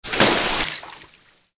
SA_watercooler_spray_only.ogg